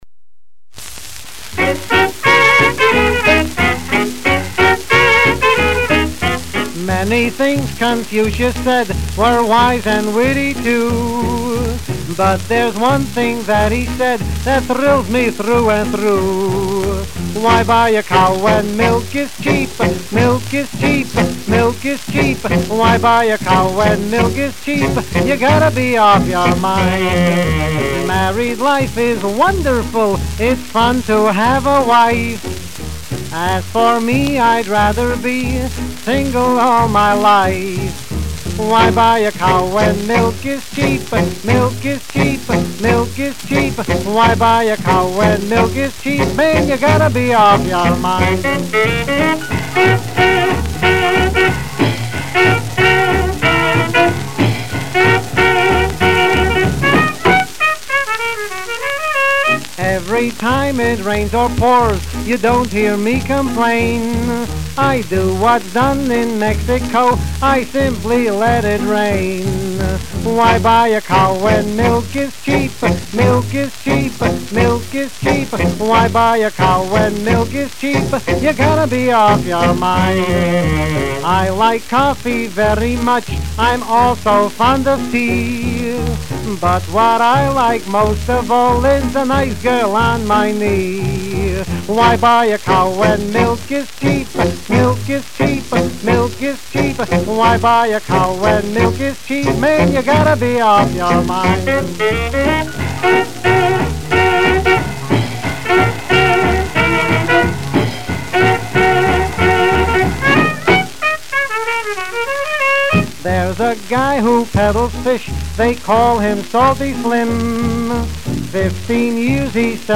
double entendre party record